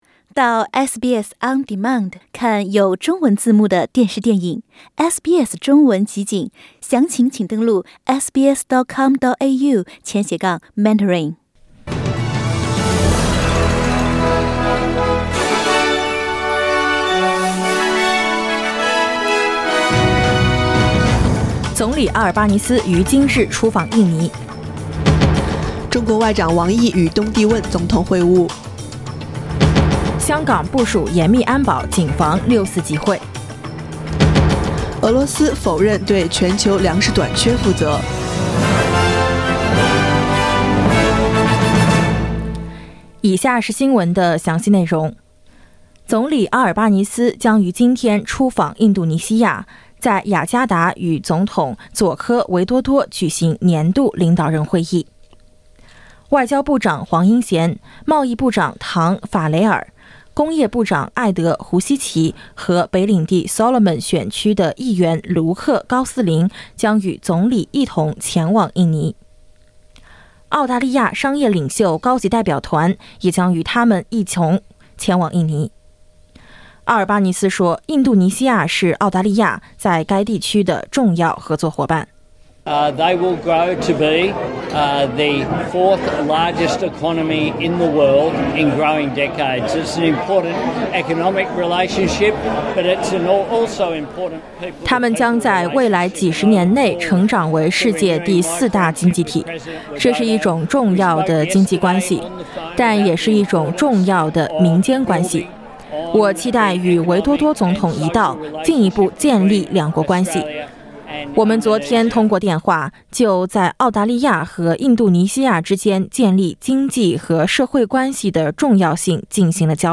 SBS早新闻（2022年6月5日）